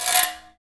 Index of /90_sSampleCDs/Roland L-CD701/PRC_Trash+Kitch/PRC_Kitch Tuned
PRC GRATER 2.wav